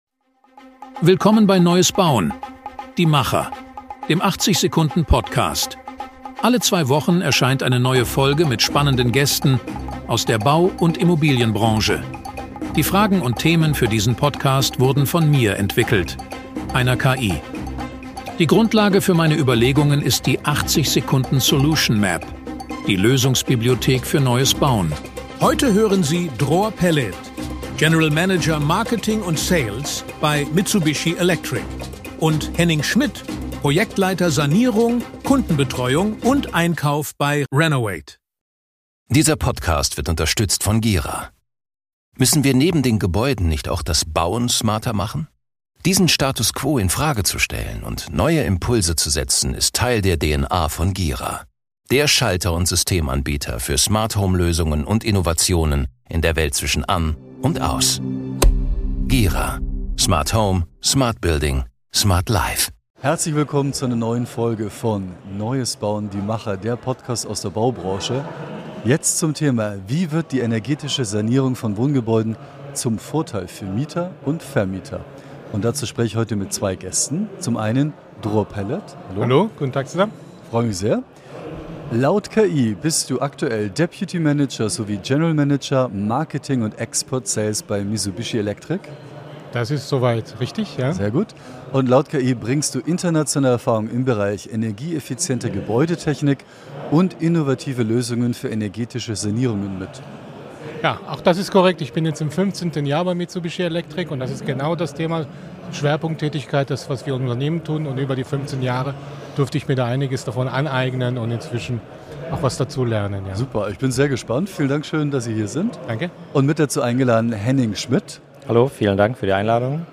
Ein praxisnahes Gespräch über Effizienz, Klimaschutz und Komfort – präsentiert von Gira: Smart Home.